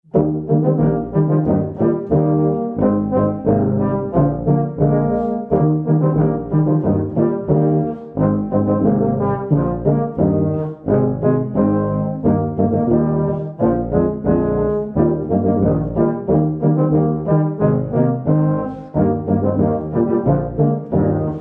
The Christmas Classic for the intermediate tuba quartet.